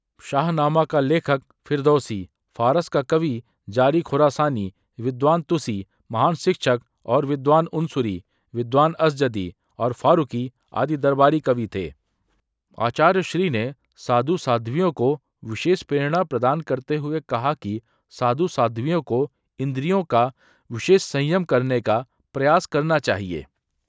TTS_multilingual_audios